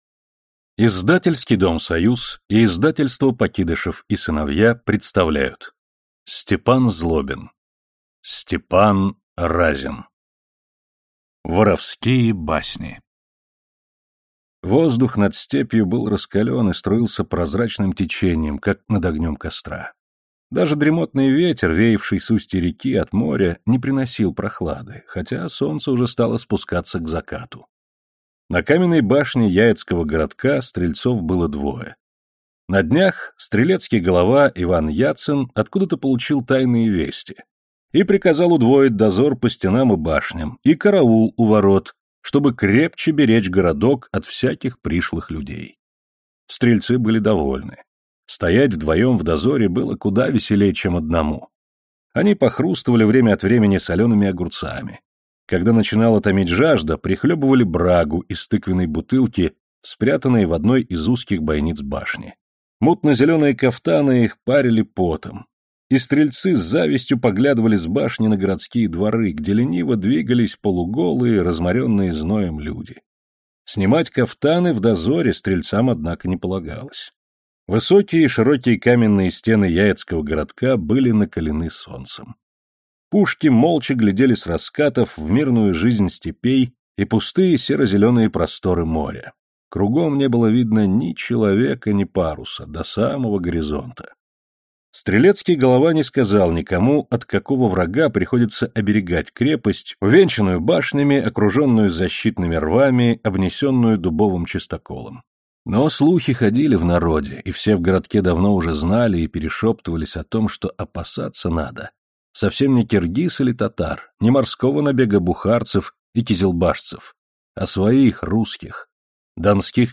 Аудиокнига Степан Разин | Библиотека аудиокниг